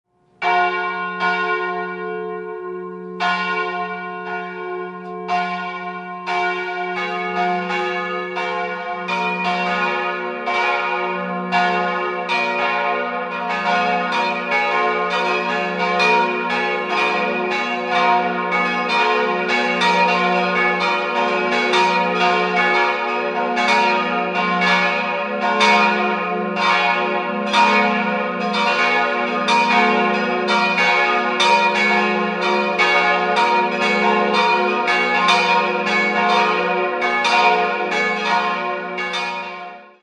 Die drei großen Glocken wurden 1952 von der Gießerei Hofweber in Regensburg gegossen und sind auf die Töne f'+8, as'+8 und b'+7 gestimmt. Die kleinste Glocke (Ave-Glocke) stammt von 1629 und erklingt im Ton es''-2.